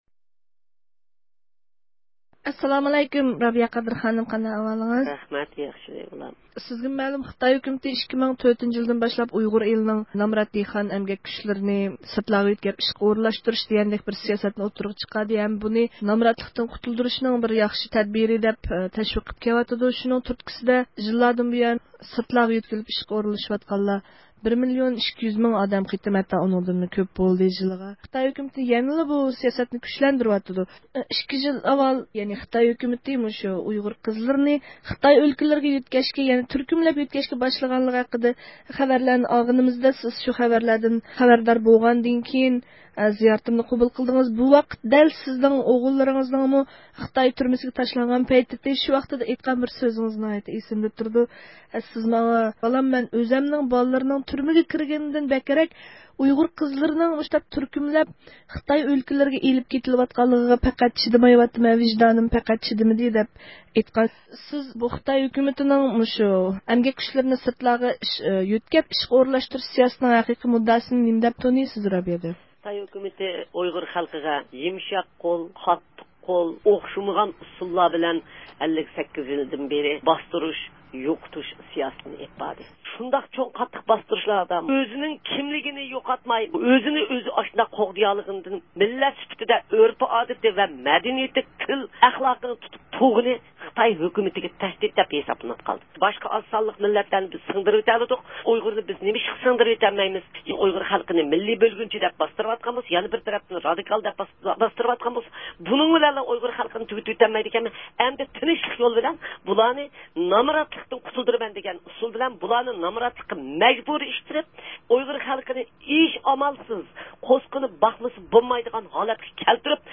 سۆھبىتىگە دىققەت بەرگەيسىلەر.